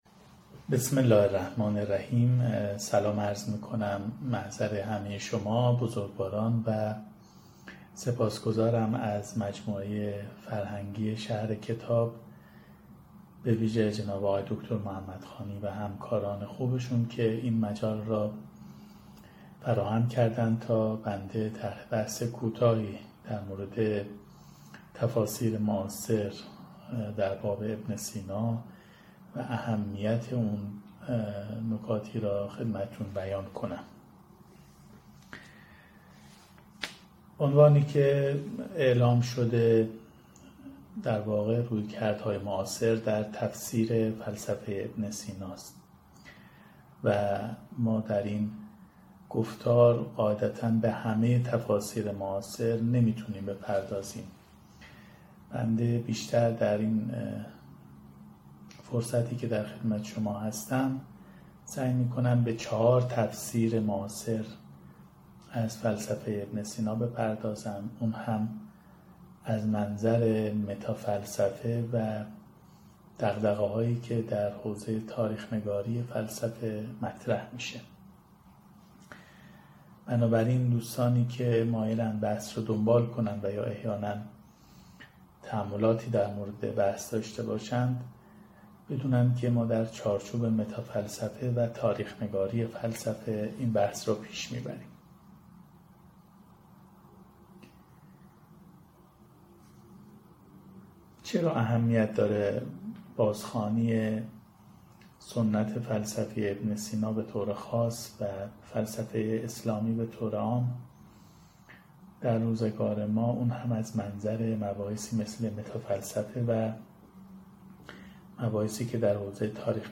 سی‌ویکمین نشست از مجموعه درس‌گفتارهایی درباره بوعلی‌سینا
این درس‌گفتار به صورت مجازی از اینستاگرام شهر کتاب پخش شد.